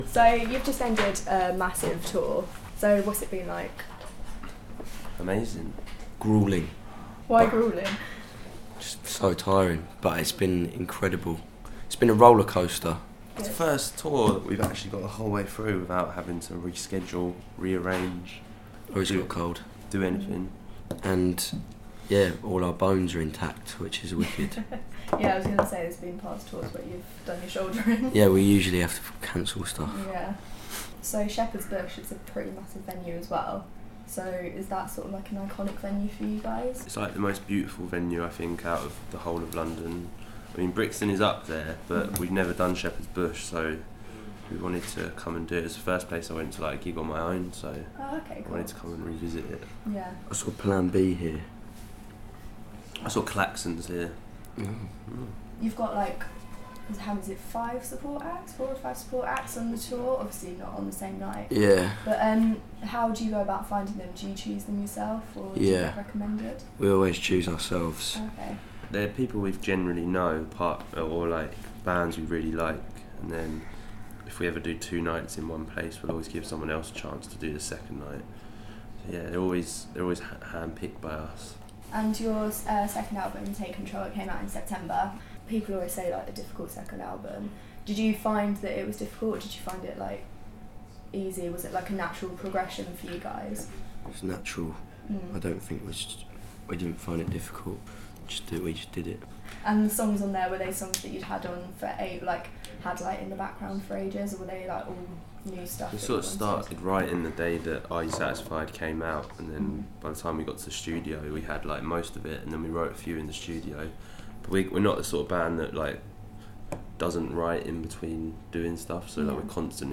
INTERVIEW: SLAVES discuss their recent tour and the touring life